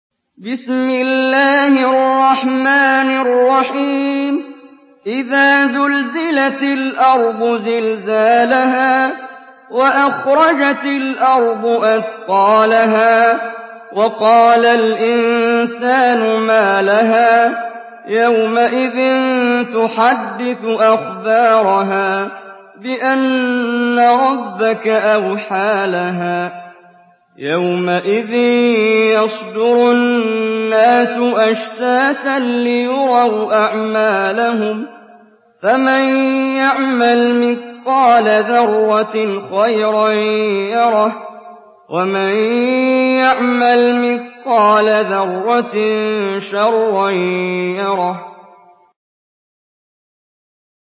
Surah আয-যালযালাহ্ MP3 in the Voice of Muhammad Jibreel in Hafs Narration
Murattal Hafs An Asim